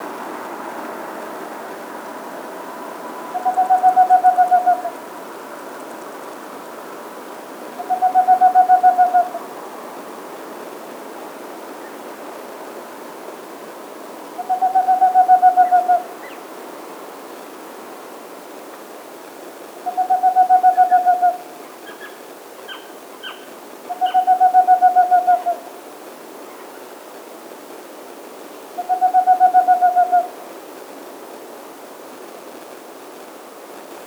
11. Whiskered Screech-Owl (Megascops trichopsis)
• Sound: Soft whinnies & trills